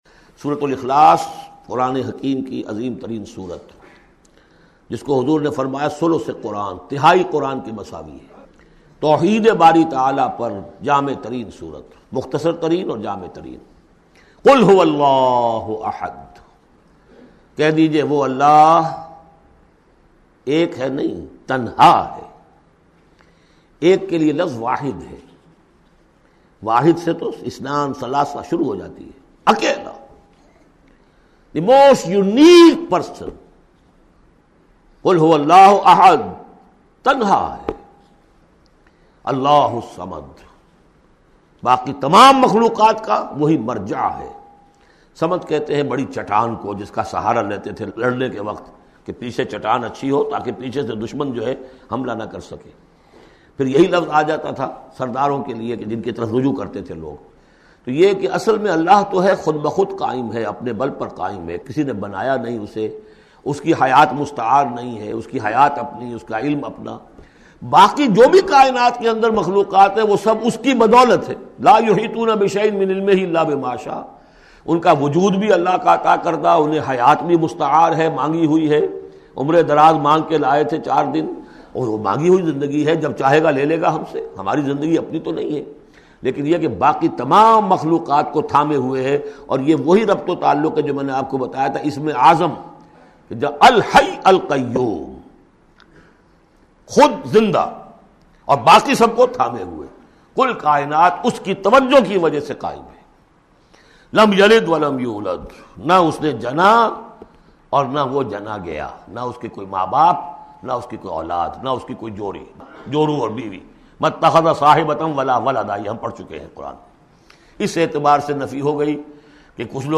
Surah Ikhlas, listen online mp3 tafseer in the voice of Dr Israr Ahmed.